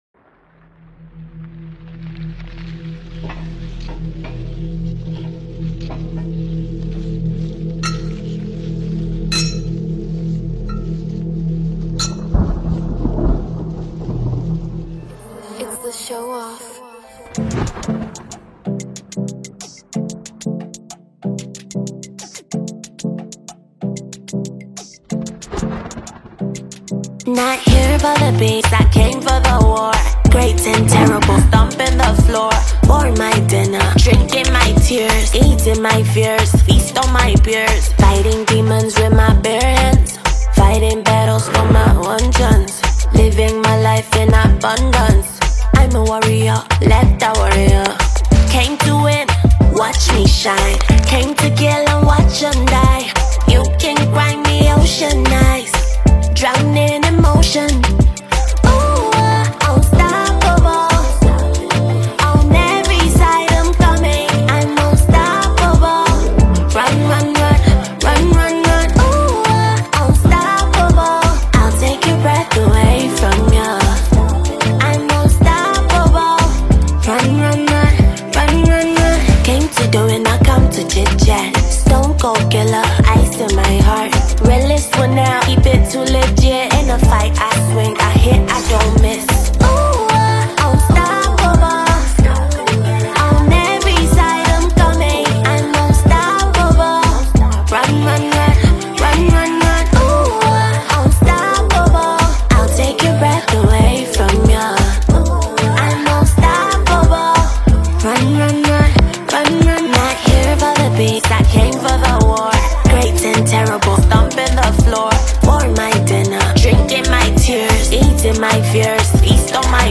alternative R&B, hip hop vocalist